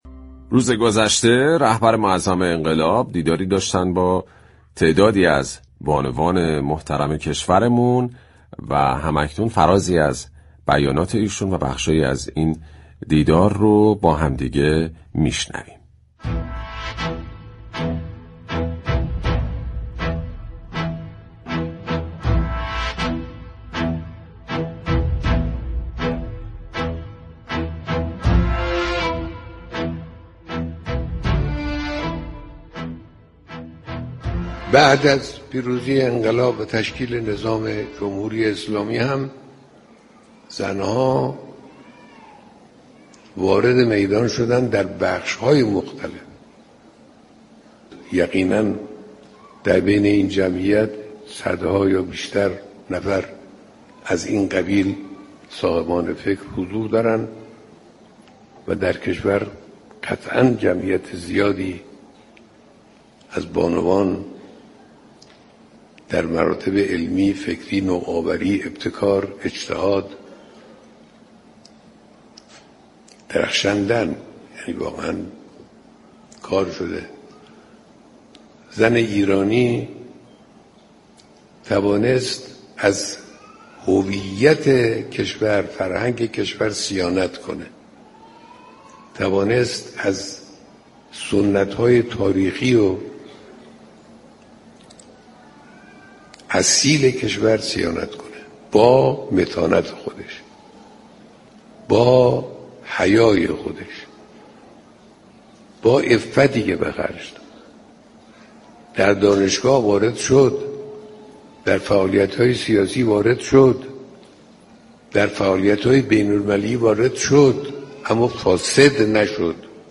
در گفت و گو با «بام تهران» درخصوص دیدار رهبر انقلاب با جمعی از بانوان كشور